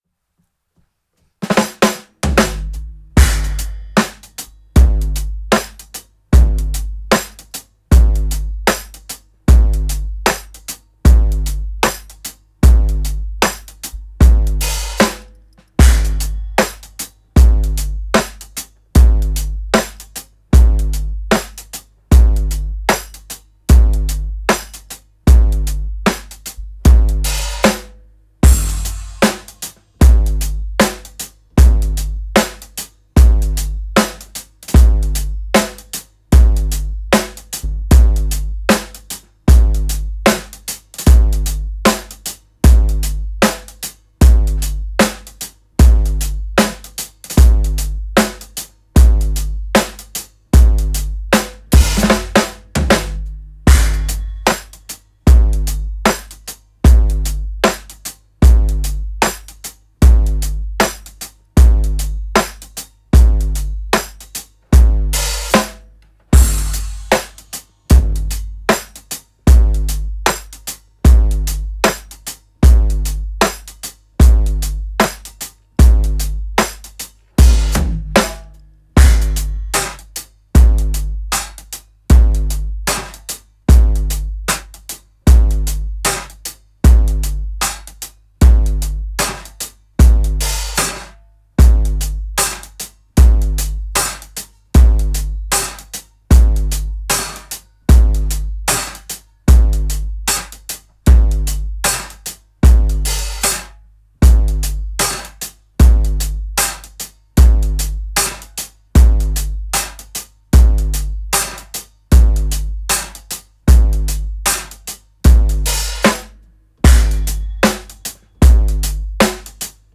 Wonky Pop
Genre:Wonky Pop, Hip-hop
Tempo:76 BPM (4/4)
Kit:Audition Japanese vintage 16"
Mics:14 channels